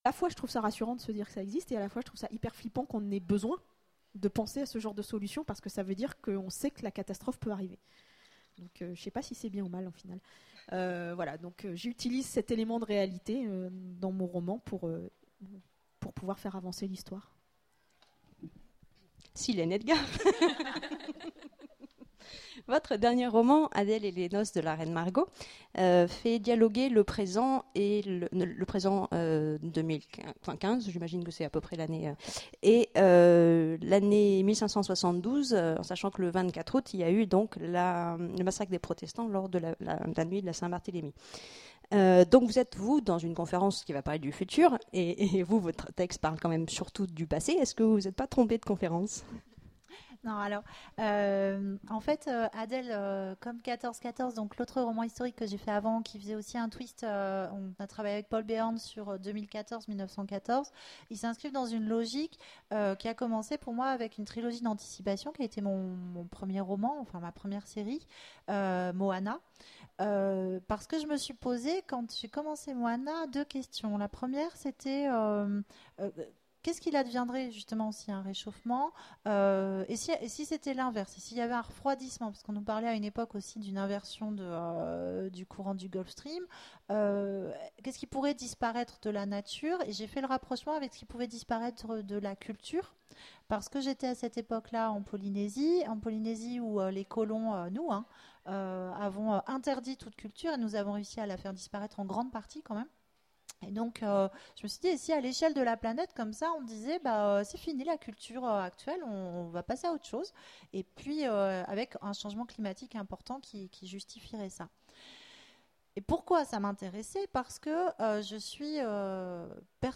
Imaginales 2015 : Conférence Littérature de jeunesse
Suite à un problème technique, il manque les dix premières minutes de table ronde.